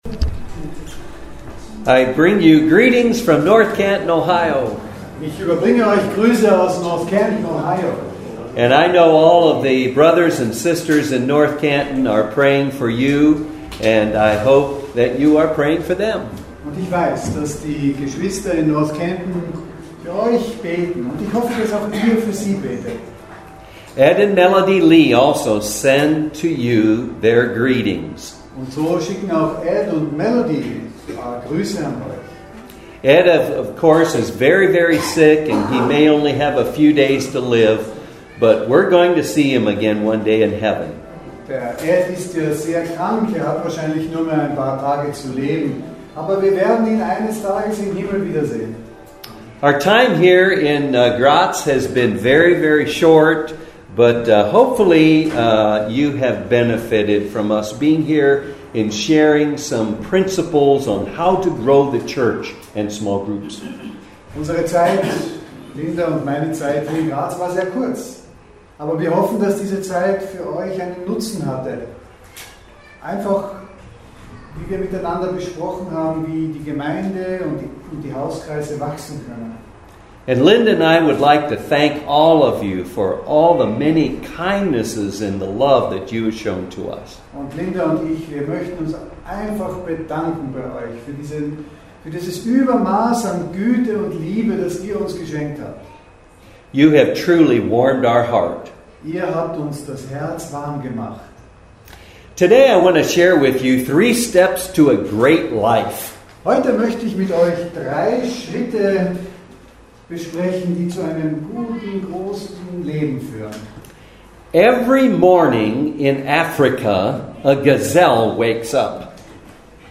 Diese Predigt ist auf Englisch mit Simultanübersetzung ins Deutsche.